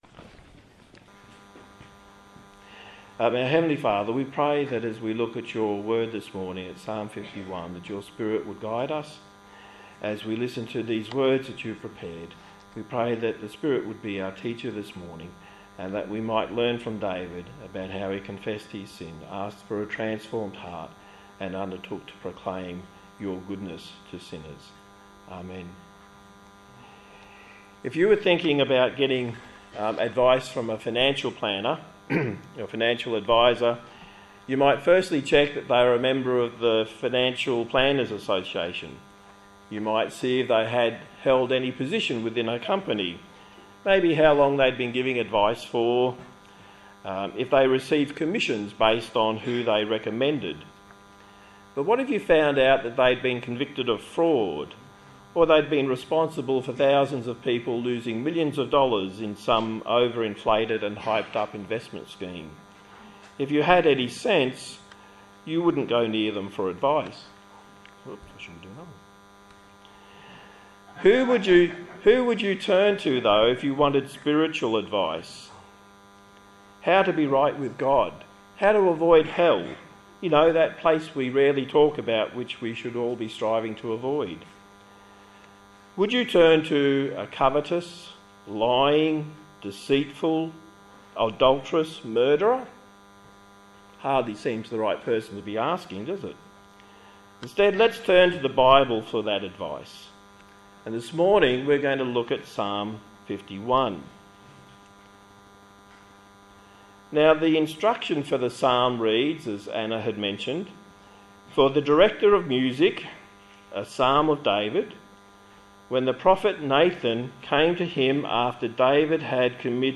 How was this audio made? Psalms Passage: Psalm 51 Service Type: Morning Service